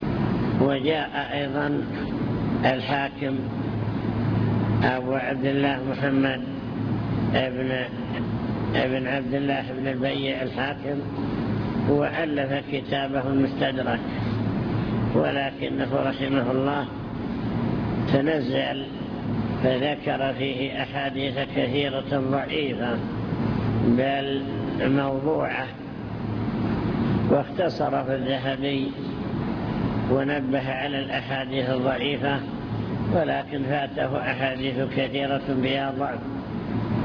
المكتبة الصوتية  تسجيلات - محاضرات ودروس  محاضرات بعنوان: عناية السلف بالحديث الشريف من جاء بعد أصحاب الكتب الستة